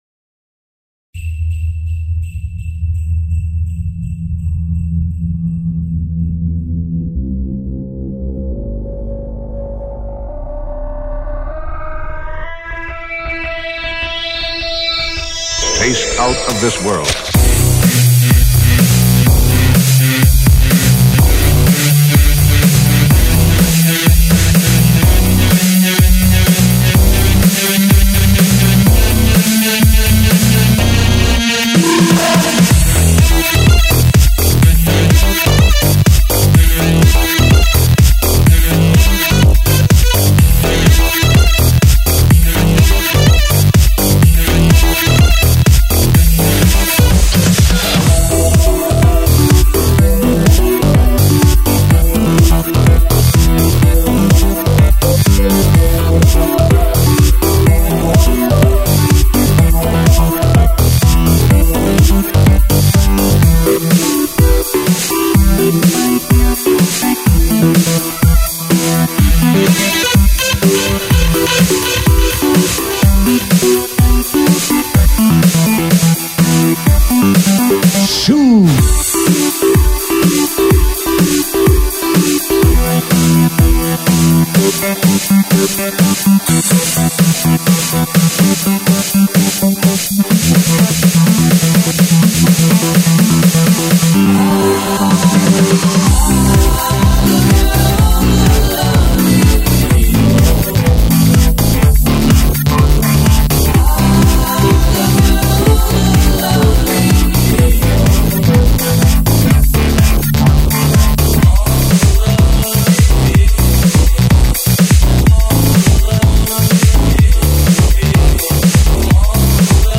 реворк